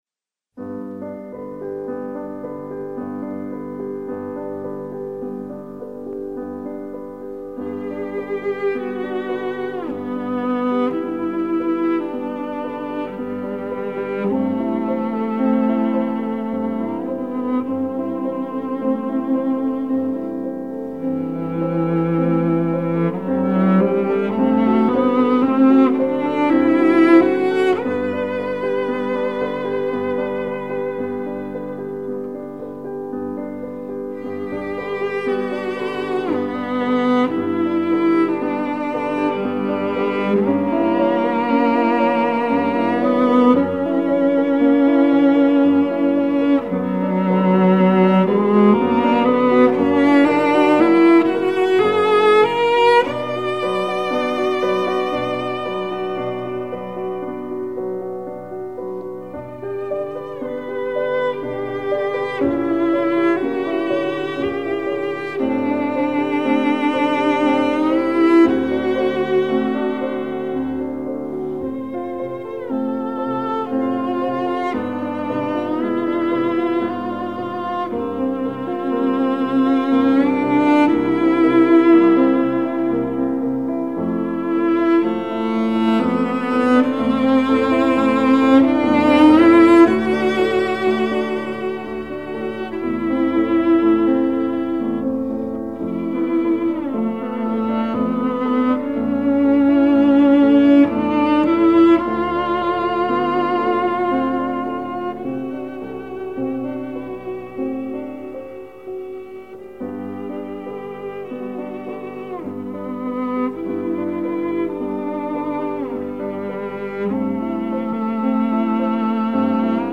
виолончель
ф-но